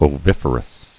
[o·vif·er·ous]